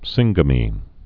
(sĭnggə-mē)